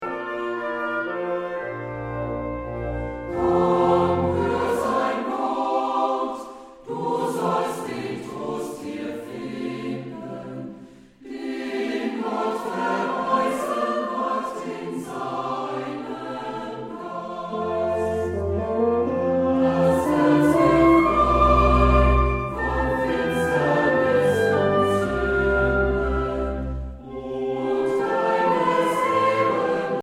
Instrumentalsätze für variable Besetzungen